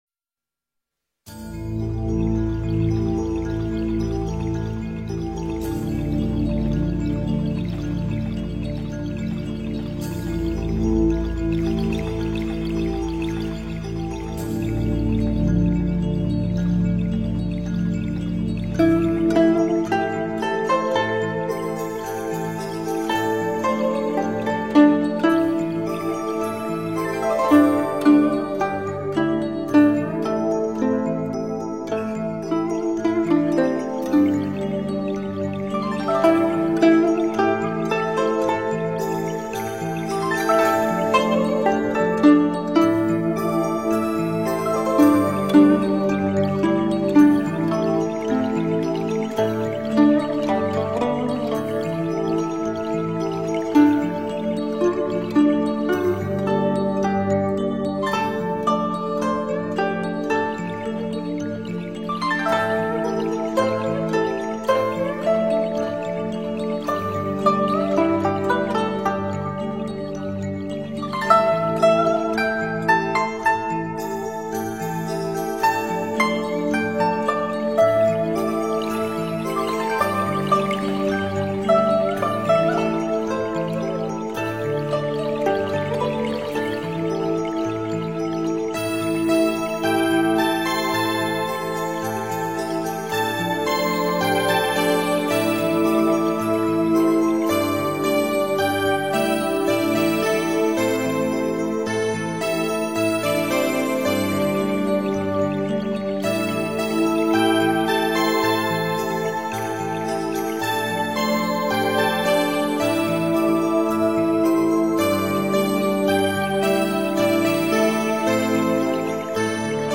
佛音 诵经 佛教音乐 返回列表 上一篇： 古剎晚鐘(二